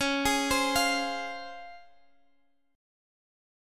C#M7 Chord